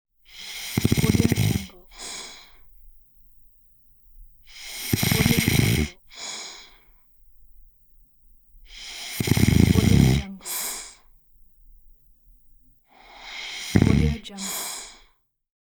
دانلود افکت صوتی صدای خروپف کردن مرد در خواب